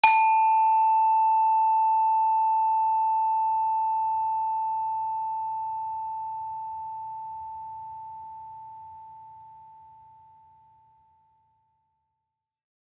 HSS-Gamelan-1
Saron-2-A4-f.wav